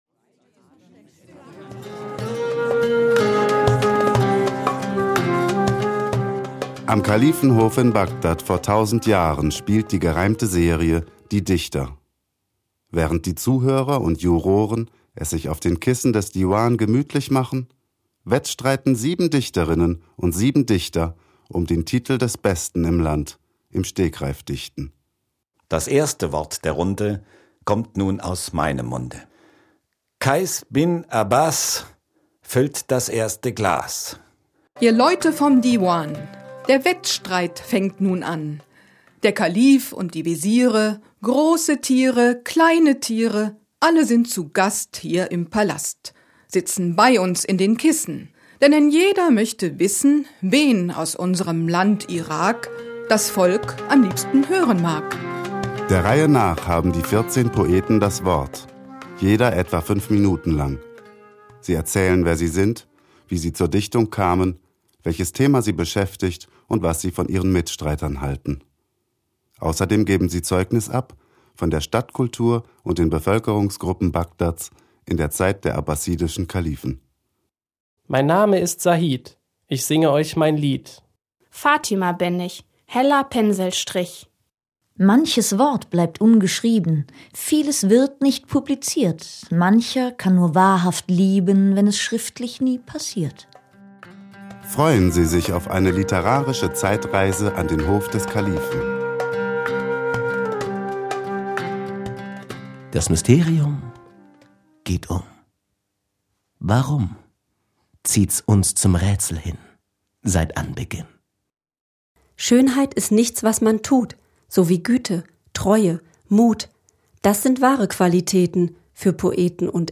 mp3: Audio-Trailer der CD „Die Dichter“ (4:27 min) 4 MB
Hörspiel „Die Dichter“ entführt nach Bagdad vor 1000 Jahren